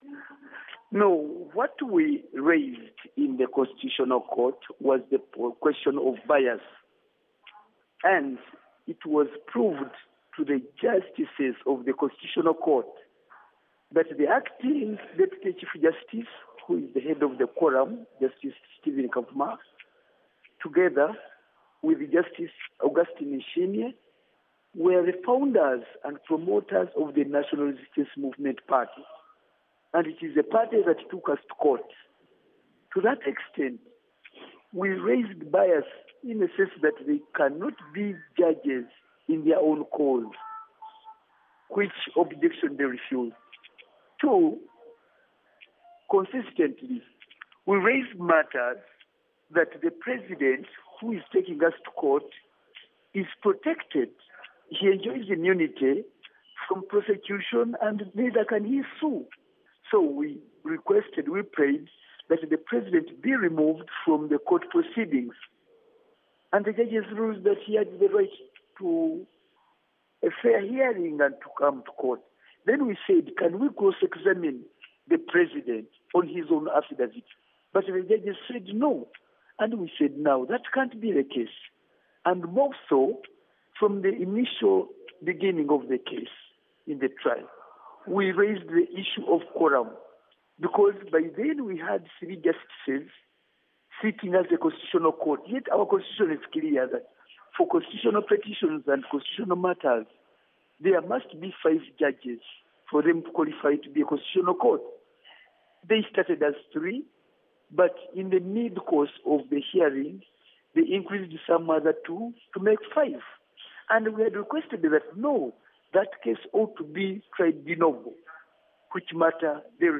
interview with Theodore Ssekikubo, Ugandan Legislator